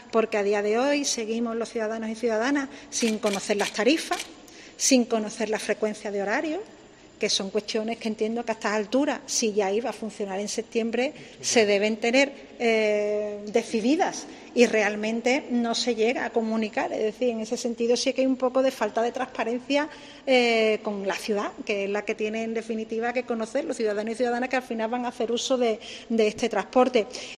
Patricia Cavada, alcaldesa de San Fernando, sobre el tranvia